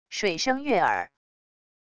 水声悦耳wav音频